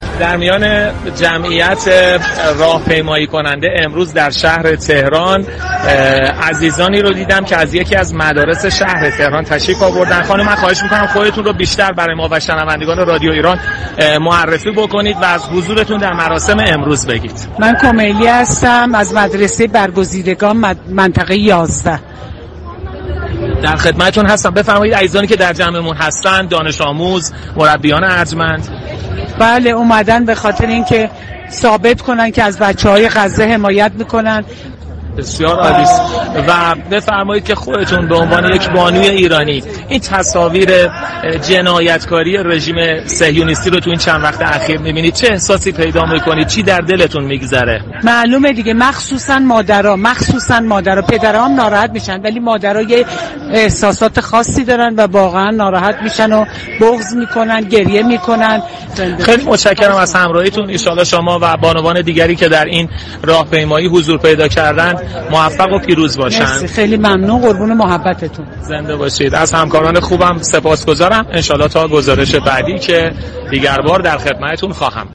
از یك مربی كه به همراه دانش آموزانش در راهپیمایی گسترده برای همصدایی با مادران غزه آمده است گفت و گو كرده است